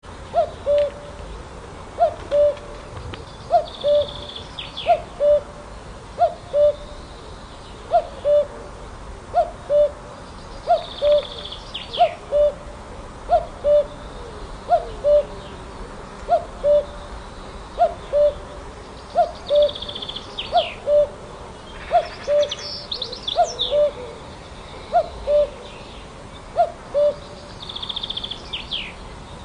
春天里的布谷鸟叫声 大杜鹃叫声